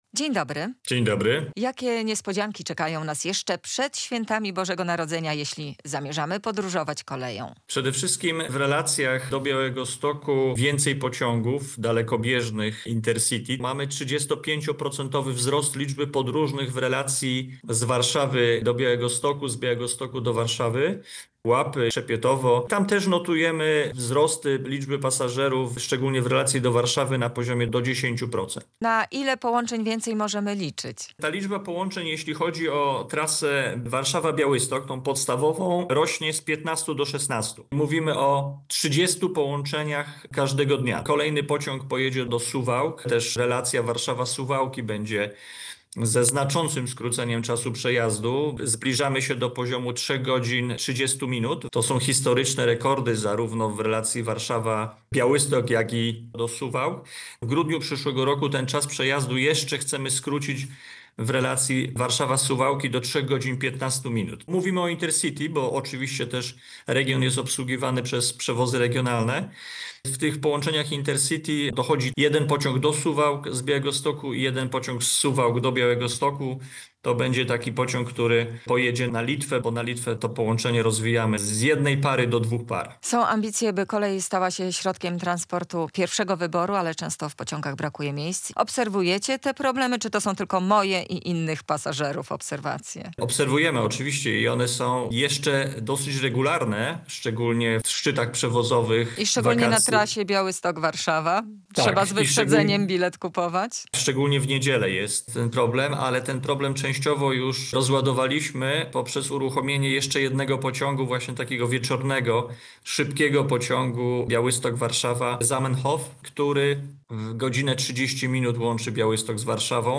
O nowym rozkładzie jazdy z wiceministrem infrastruktury Piotrem Malepszakiem rozmawia